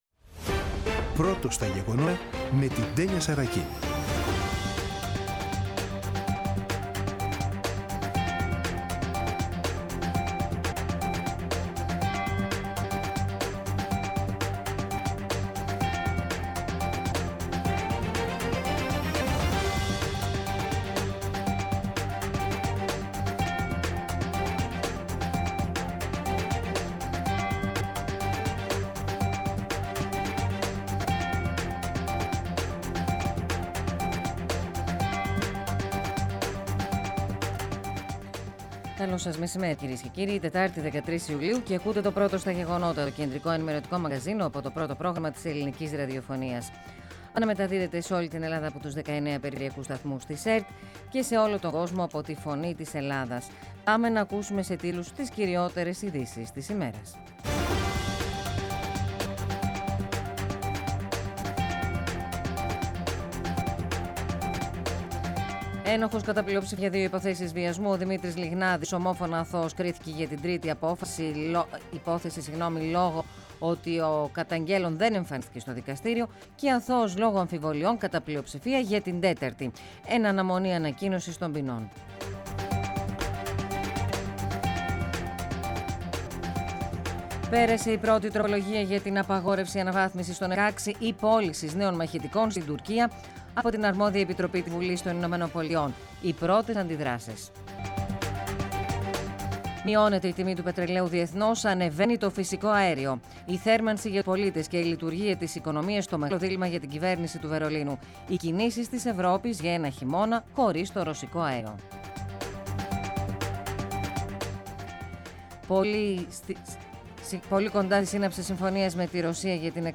“Πρώτο στα γεγονότα”. Το κεντρικό ενημερωτικό μαγκαζίνο του Α΄ Προγράμματος , από Δευτέρα έως Παρασκευή στις 14.00. Με το μεγαλύτερο δίκτυο ανταποκριτών σε όλη τη χώρα, αναλυτικά ρεπορτάζ και συνεντεύξεις επικαιρότητας.